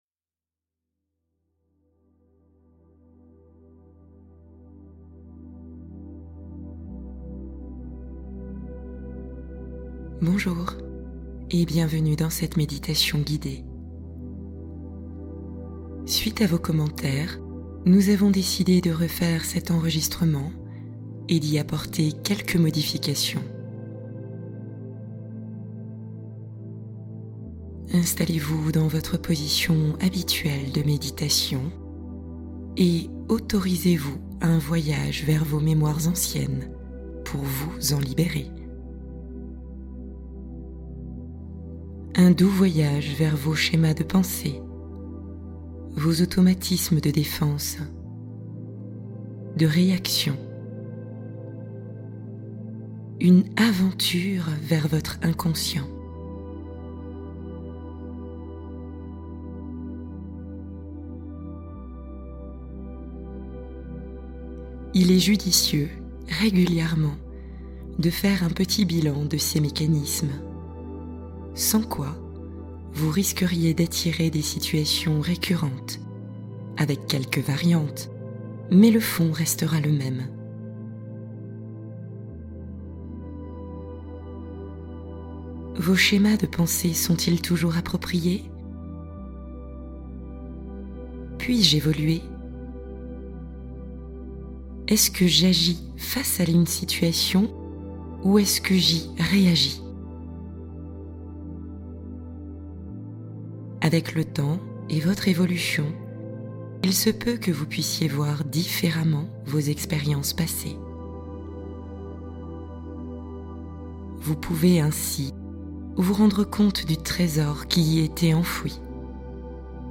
Libérez les mémoires qui vous emprisonnent | Méditation pour effacer les blessures anciennes